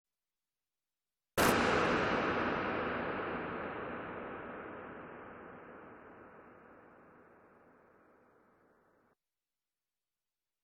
6.5 scene 6 water crossfade.MP3